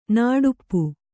♪ nāḍuppu